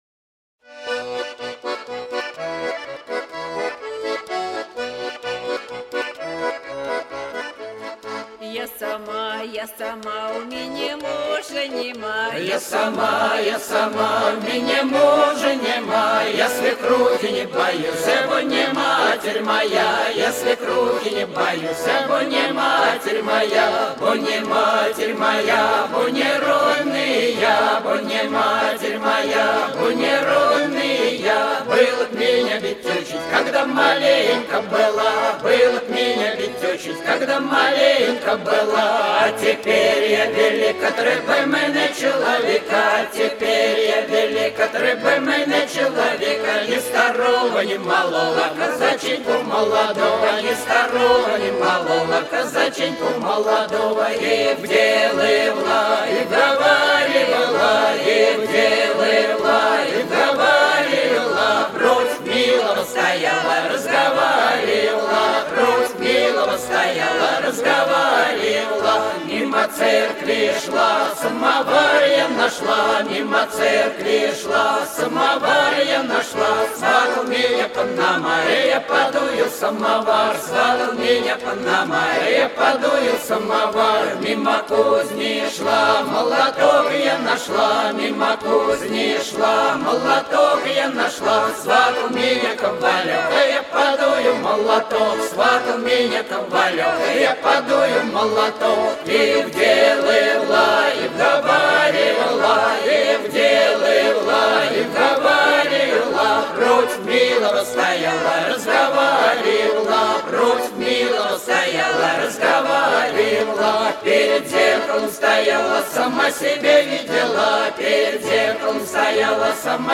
• Жанр: Детские песни
Ансамбль казачьей песни